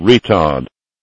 IDG-A32X/Sounds/GPWS/retard.wav at 5ebf97113a4cc095f63e4ab5c1900ab07147b73a